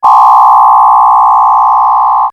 RetroGamesSoundFX / Hum / Hum31.wav
Hum31.wav